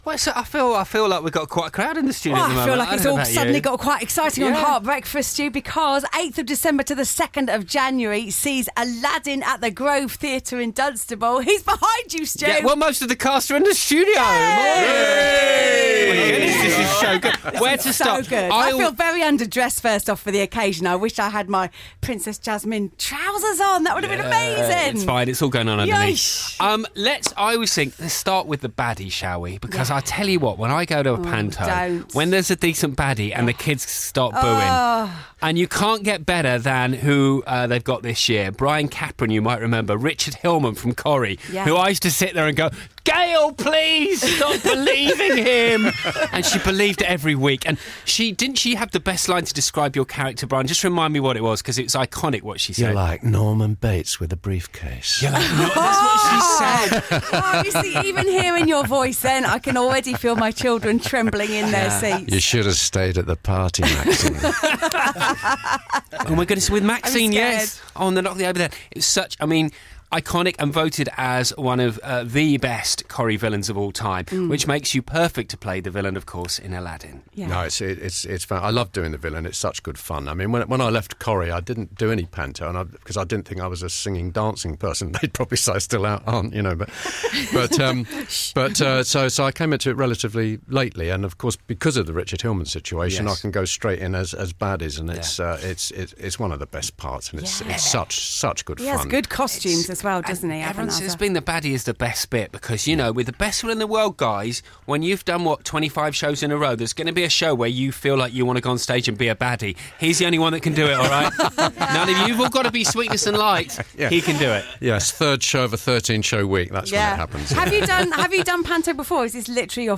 The Grove Theatre Panto 2016 Cast Interview
We crammed the whole cast into our lamp, I mean Studio to chat about Aladin on this Christmas.